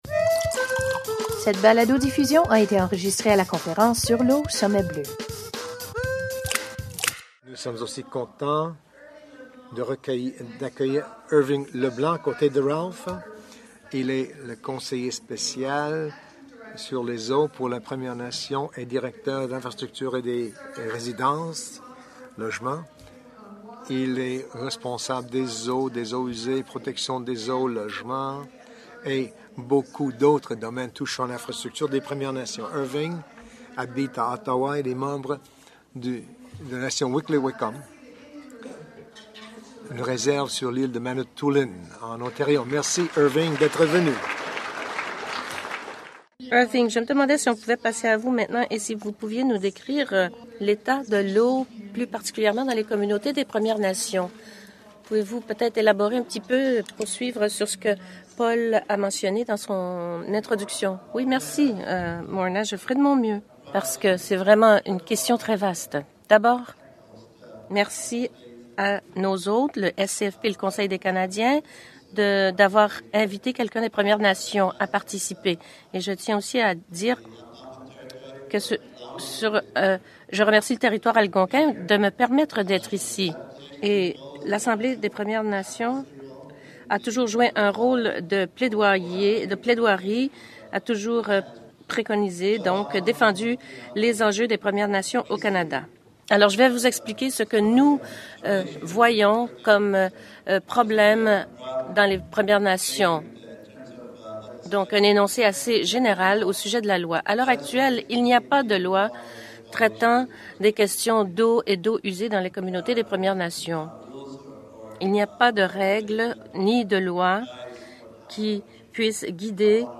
Au Sommet bleu, il a répondu à une question sur l’état de l’eau dans les communautés des Premières Nations